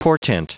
Prononciation du mot portent en anglais (fichier audio)
Prononciation du mot : portent